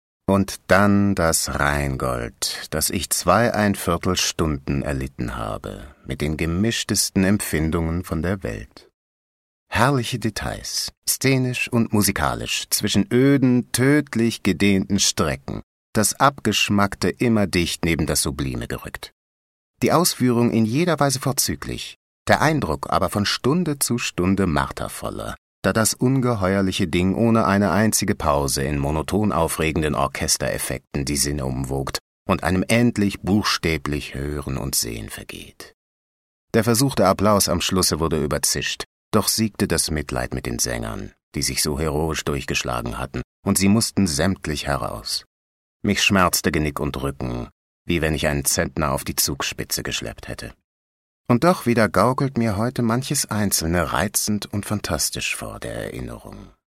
Audioguide: Richard Wagner